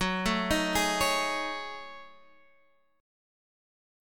F#m6add9 chord